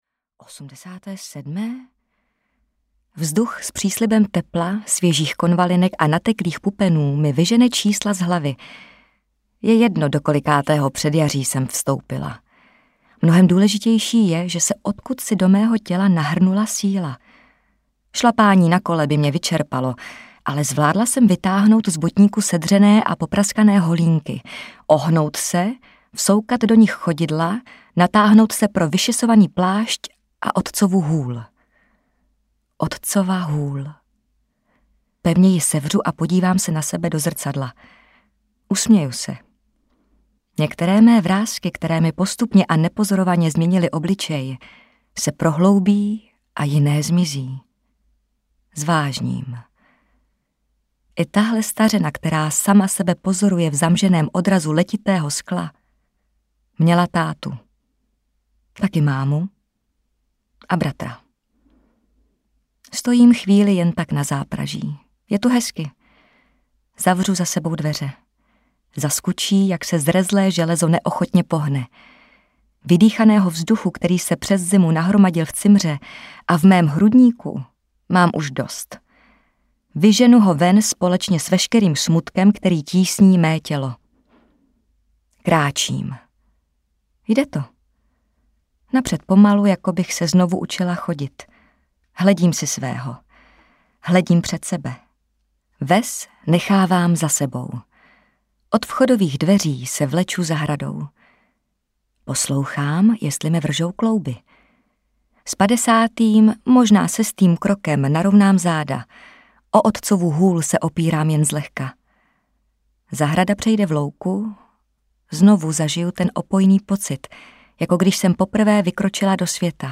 Cyklistka audiokniha
Ukázka z knihy
• InterpretDagmar Čárová, Jana Plodková